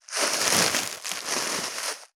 666コンビニ袋,ゴミ袋,スーパーの袋,袋,買い出しの音,ゴミ出しの音,袋を運ぶ音,
効果音